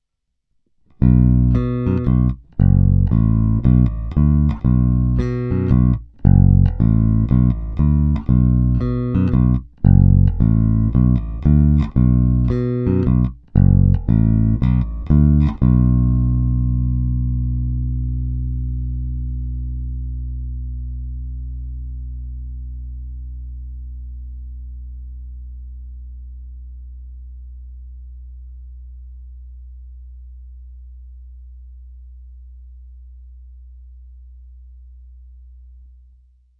Zvukově je to opravdu typický Jazz Bass se vším všudy.
Není-li uvedeno jinak, následující nahrávky jsou provedeny rovnou do zvukové karty, jen normalizovány, jinak ponechány bez úprav.
Snímač u krku